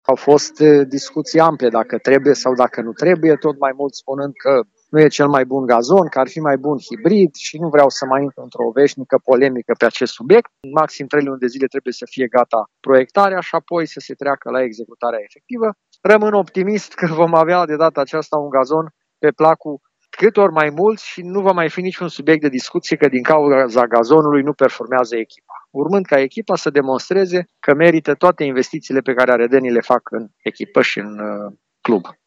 Primarul Aradului, Călin Bibarț, speră ca prin această investiție, gazonul stadionului să nu mai reprezinte un subiect de polemică: